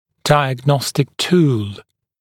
[ˌdaɪəg’nɔstɪk tuːl][ˌдайэг’ностик ту:л]диагностический метод, способ диагностики, вспомогательное средство для диагностики